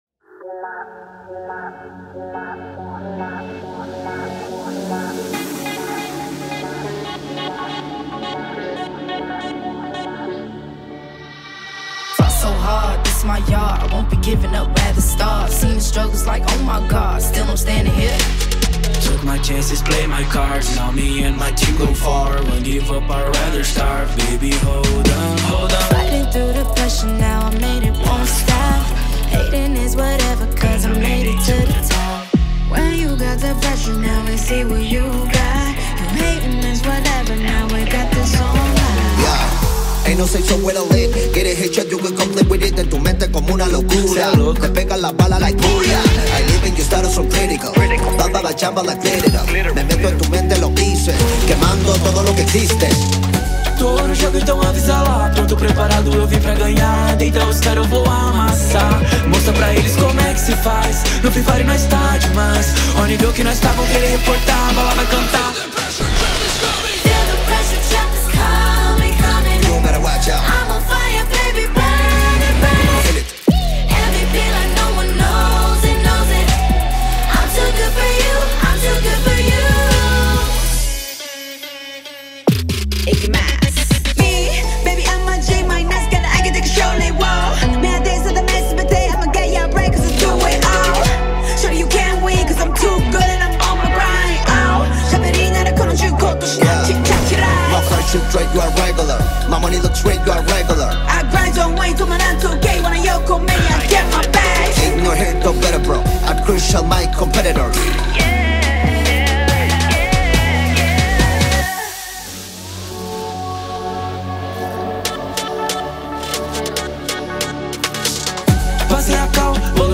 сочетая мелодичность и агрессивные биты.